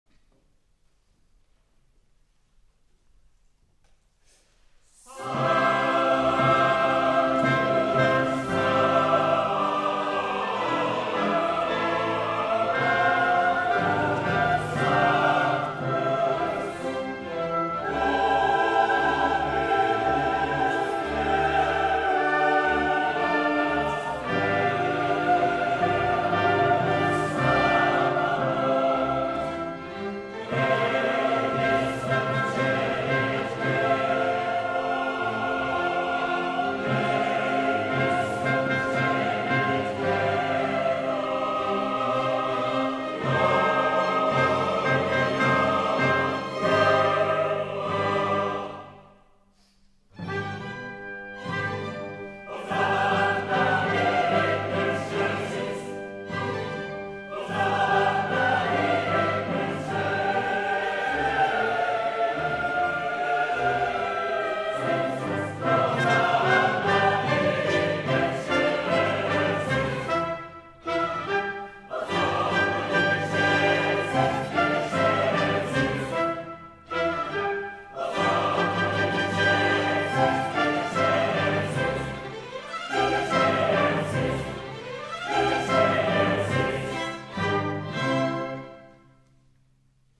第11回定期演奏会
杜のホールはしもと